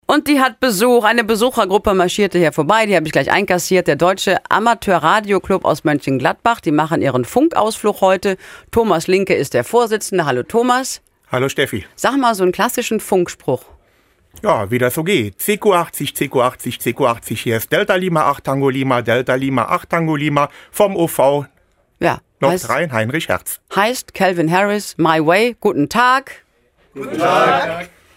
Im Studio von WDR 2 lief gerade die Steffi Neu Show.
Jetzt bekamen wir mehr als einen Eindruck, wie eine Radiosendung live produziert wird.
Ihr macht jetzt mit in der Sendung!